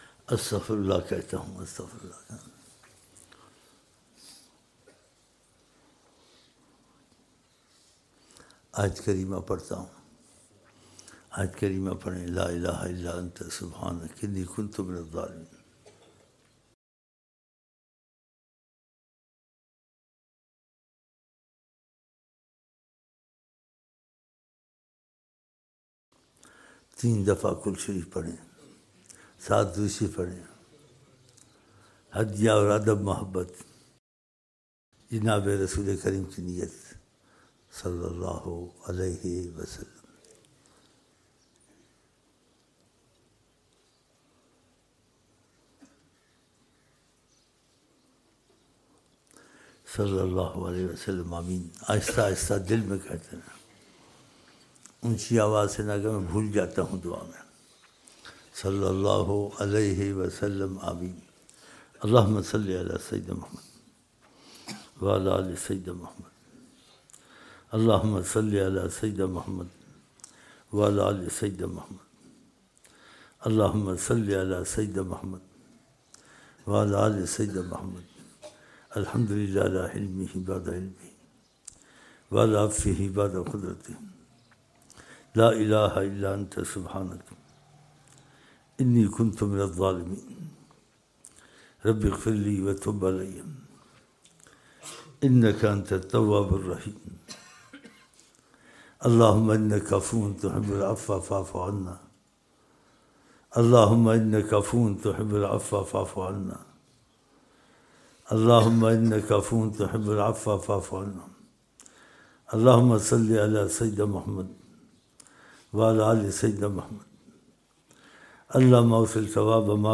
Dua 07 January 2007 Sunday Fajar Mehfil